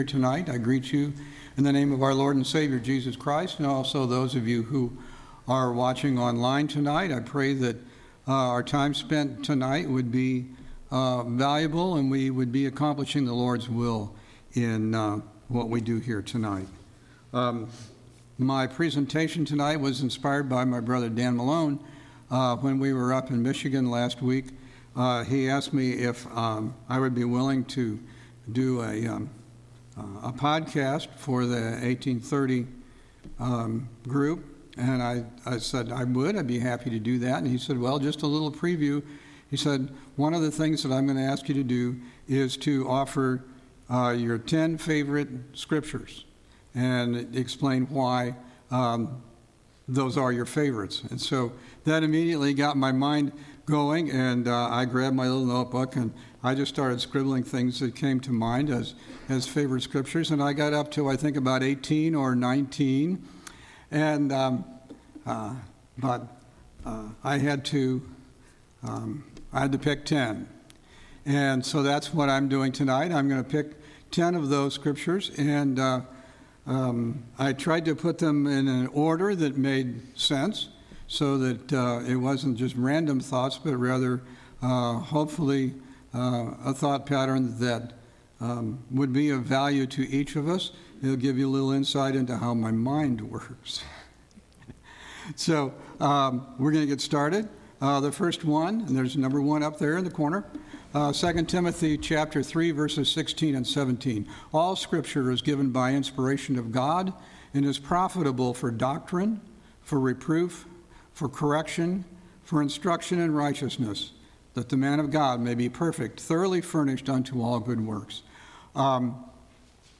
6/26/2022 Location: Temple Lot Local Event